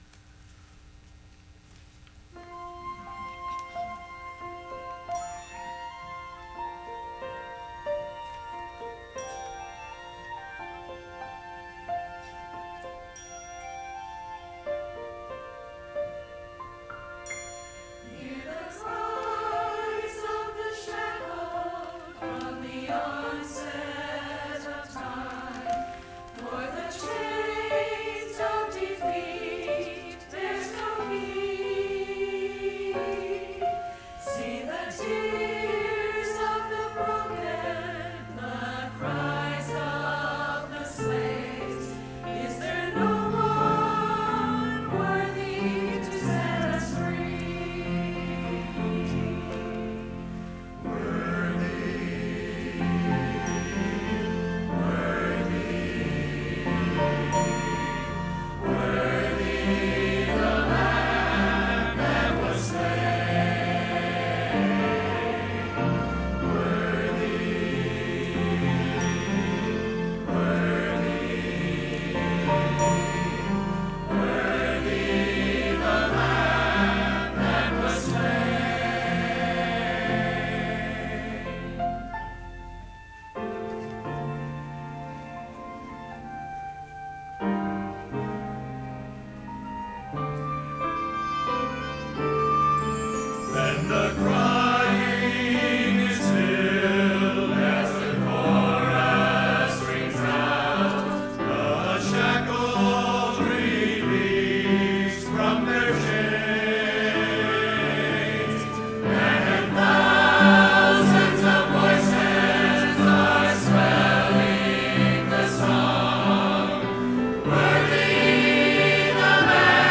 “Worthy” ~ Faith Baptist Choir and Orchestra
worthy-choir-and-orchestra.mp3